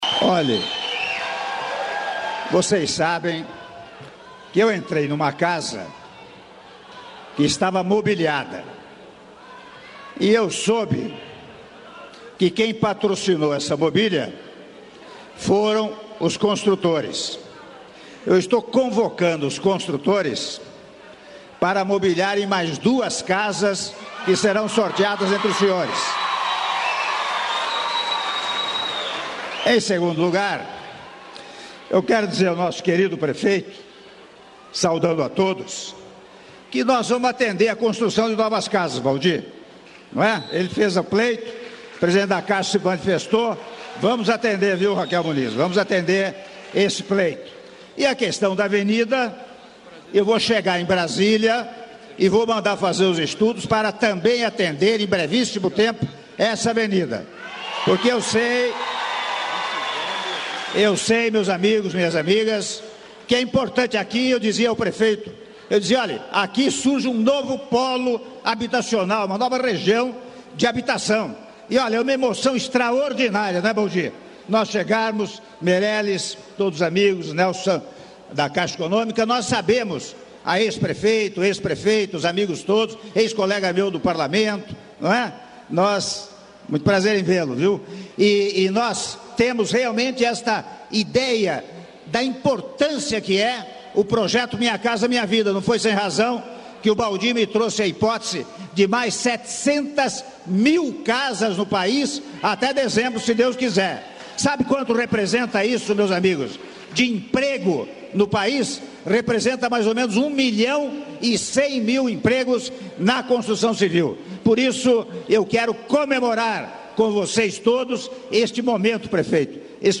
Áudio do discurso do Presidente da República, Michel Temer, durante a cerimônia de entrega de unidades habitacionais do Programa Minha Casa Minha Vida -Patos de Minas/MG- (02min31s)